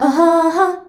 AHAAA   D.wav